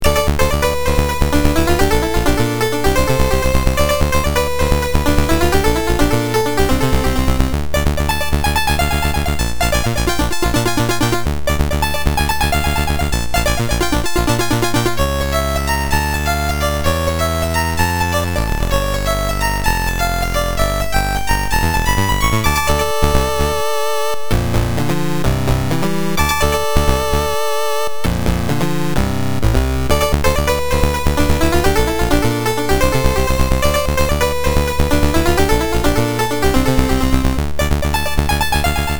VRC 6 QBASIC proggy
output from above VRC6 QBASIC proggy.